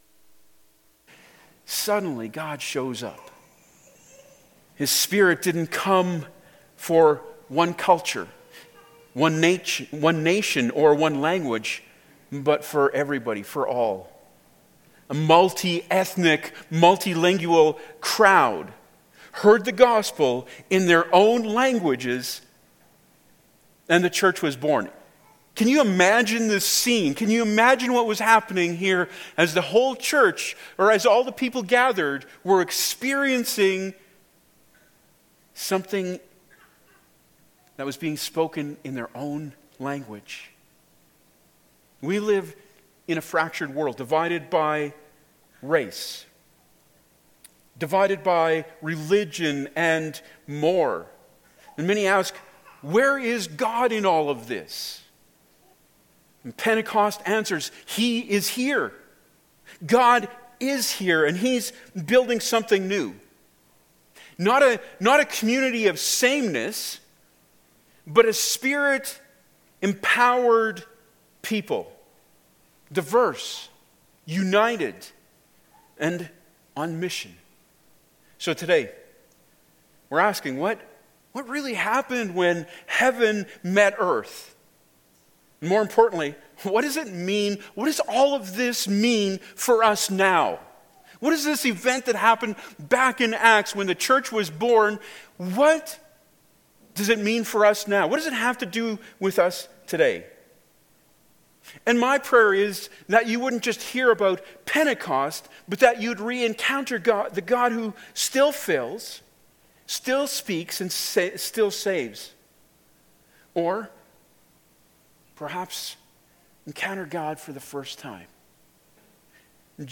Passage: Acts 2:1-21 Service Type: Sunday Morning Topics